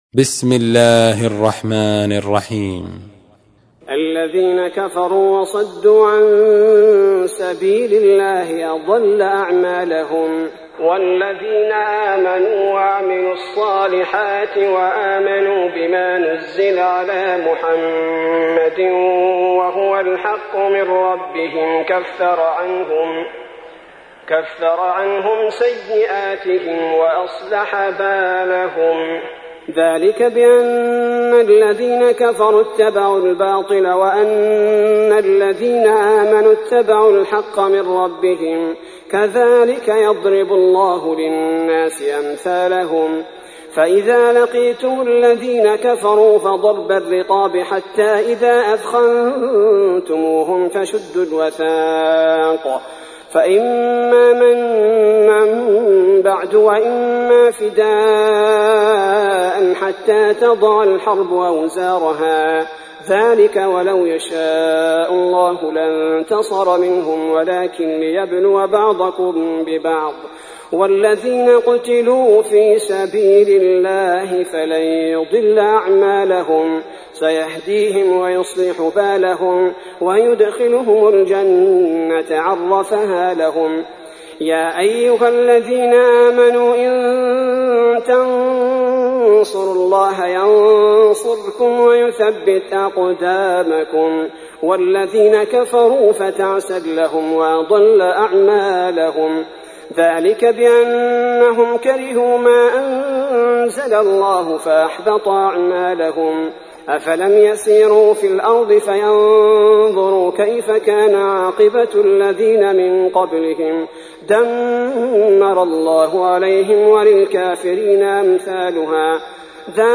تحميل : 47. سورة محمد / القارئ عبد البارئ الثبيتي / القرآن الكريم / موقع يا حسين